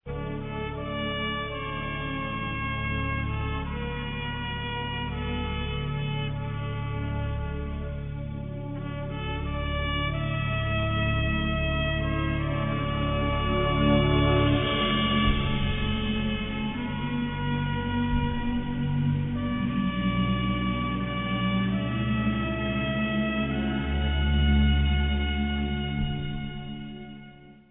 excellent trumpet theme- totally sublime!